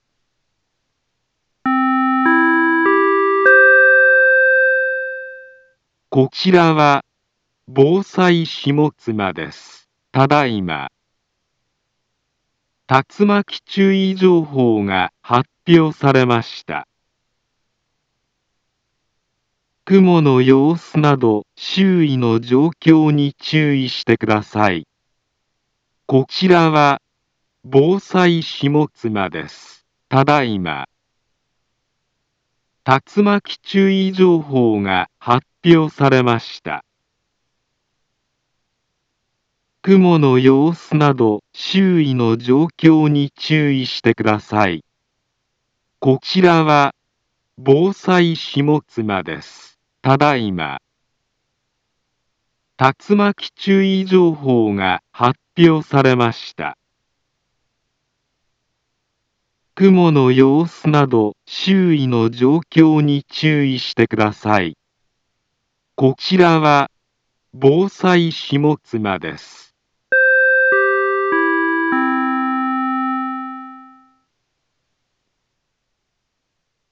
Back Home Ｊアラート情報 音声放送 再生 災害情報 カテゴリ：J-ALERT 登録日時：2021-08-30 20:59:33 インフォメーション：茨城県南部は、竜巻などの激しい突風が発生しやすい気象状況になっています。